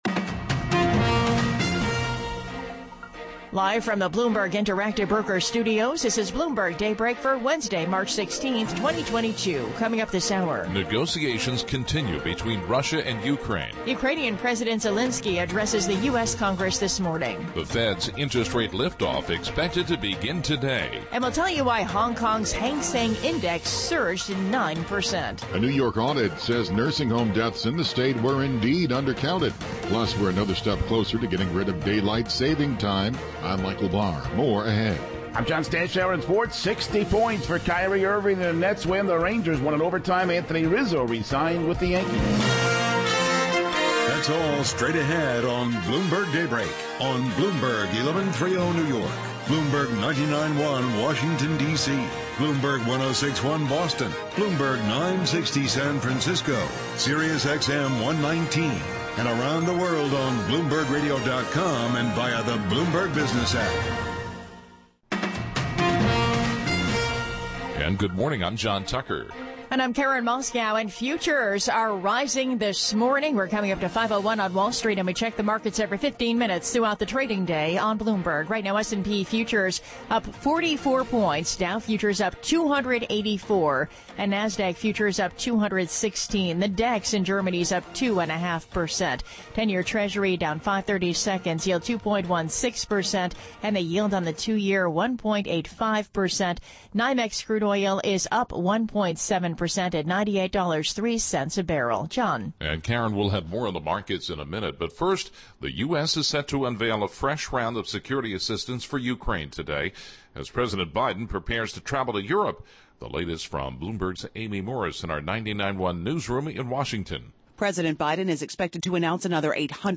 Bloomberg Daybreak: March 16, 2022 - Hour 1 (Radio) - Transcript and Chapters - from Podcast Bloomberg Daybreak: US Edition